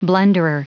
Prononciation du mot blunderer en anglais (fichier audio)
Prononciation du mot : blunderer